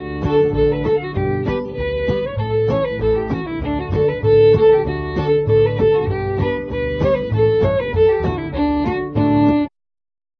Gaelic polkas!